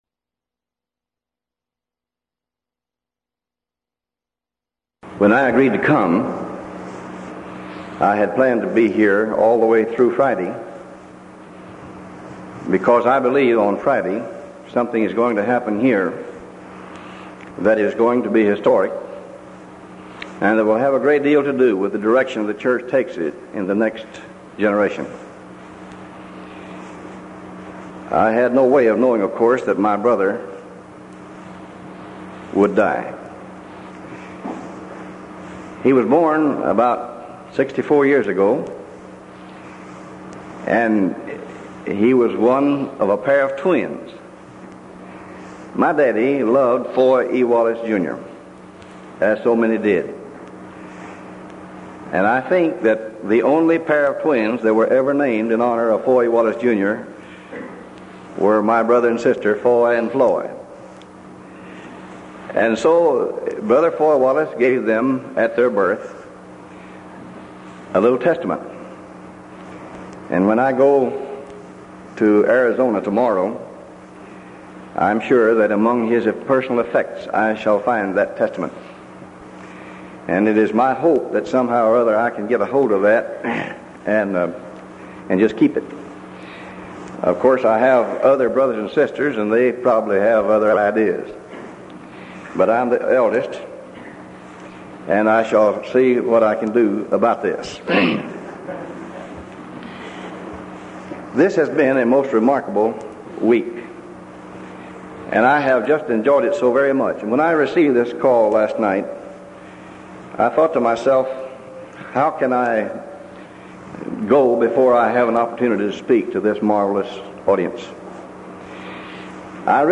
Event: 1985 Denton Lectures
lecture